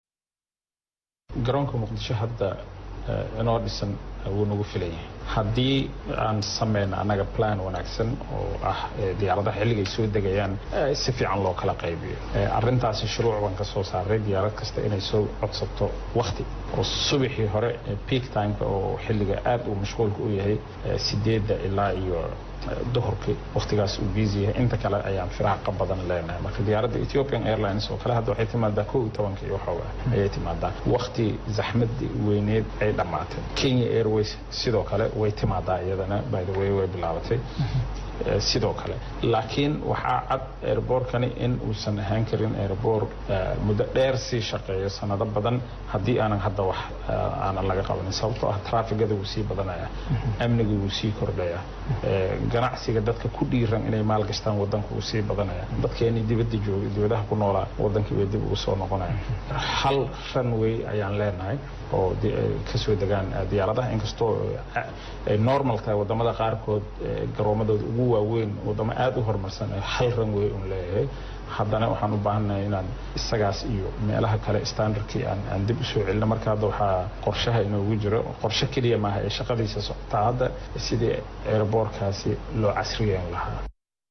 Wasiir Omaar oo la hadlayay idaacada ku hadashay afka dowlada ayaa rajo xumo ka muujiyay in garoonka Aadan Cade uusan  sii shaqayn doonin sanado badan, maadama uu san haysan garonoka meelo badan oo ay diyaradaha isku dhaafi kaaraan.
Halkaan ka dhageyso codka Wasiir  Oomaar.